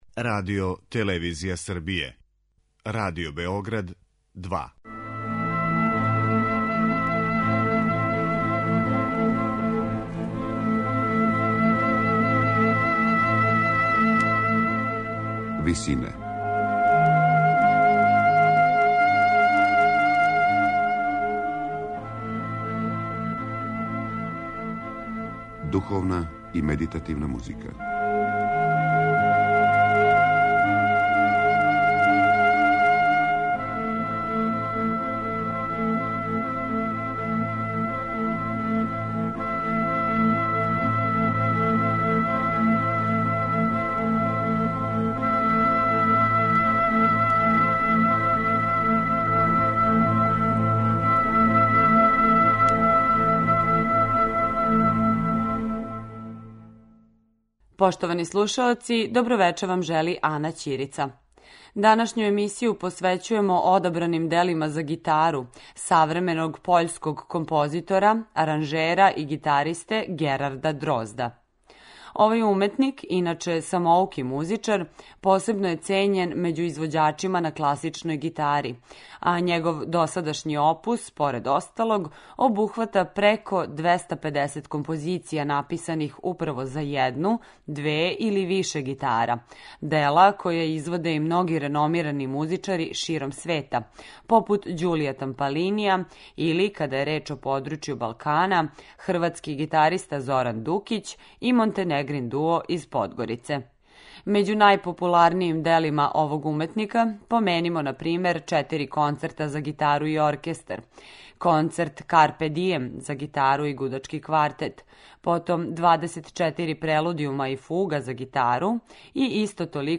Духовна и медитативна музика